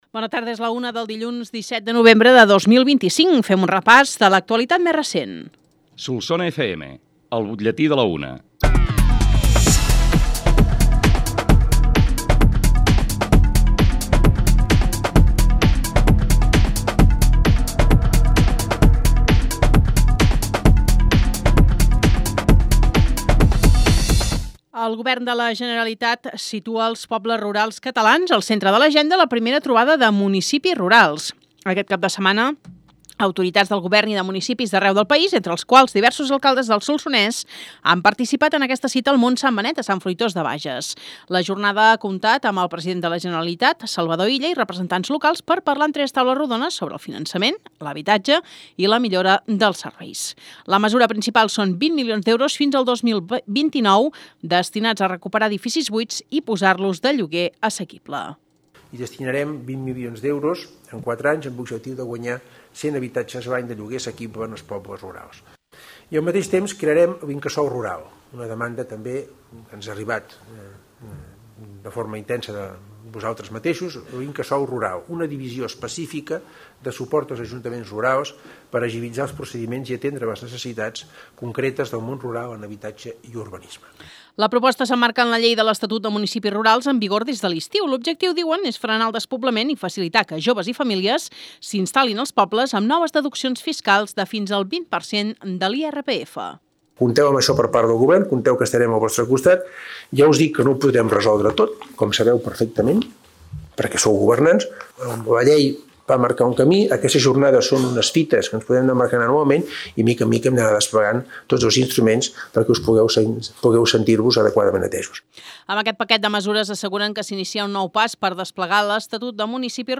L’ÚLTIM BUTLLETÍ
BUTLLETI-17-NOV-25.mp3